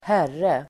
Uttal: [²h'är:e]